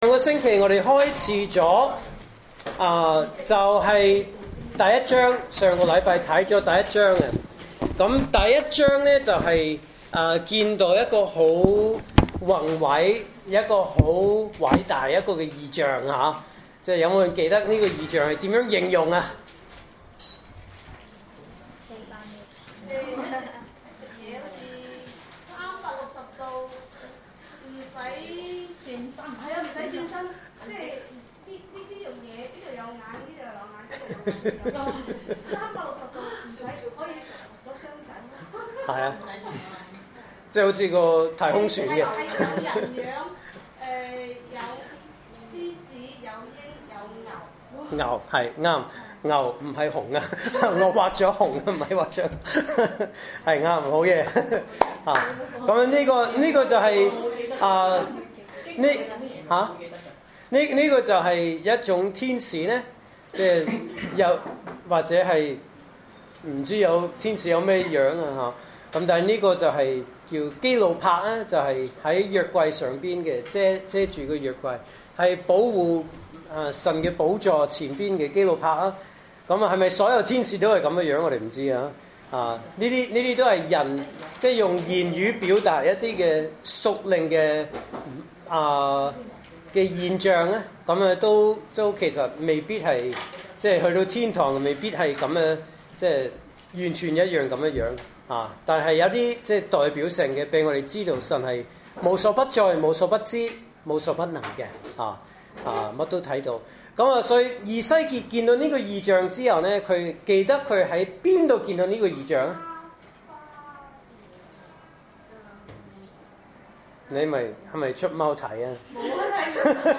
證道信息